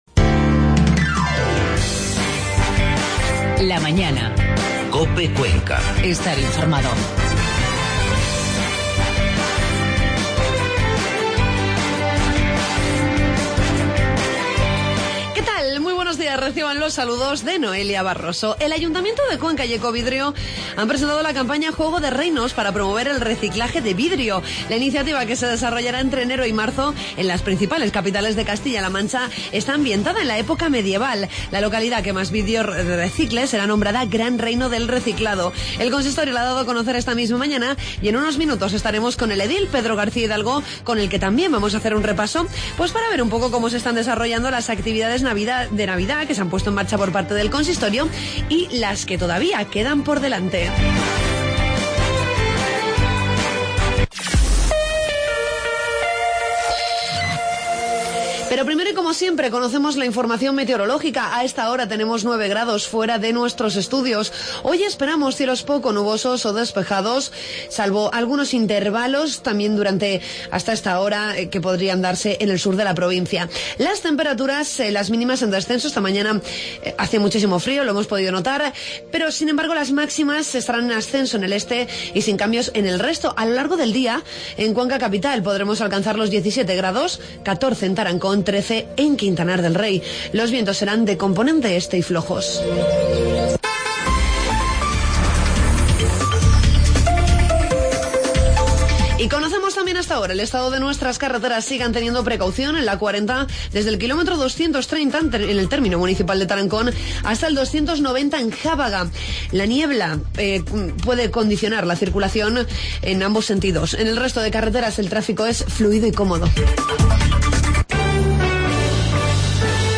Redacción digital Madrid - Publicado el 29 dic 2016, 13:25 - Actualizado 13 mar 2023, 18:22 1 min lectura Descargar Facebook Twitter Whatsapp Telegram Enviar por email Copiar enlace Hablamos con el concejal Pedro García Hidalgo de la campaña "Juego de Reinos" que promueve el Ayuntamiento y Ecovidrio para potenciar el reciclaje de este material.